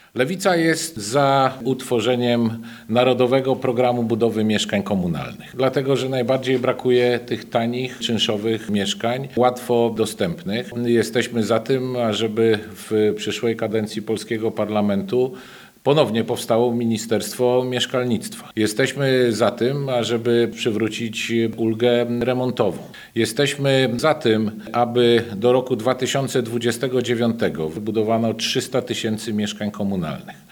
Utworzenie Narodowego Planu Budowy Mieszkań Komunalnych to postulat Nowej Lewicy, o których mówił poseł Dariusz Wieczorek podczas dzisiejszej konferencji prasowej.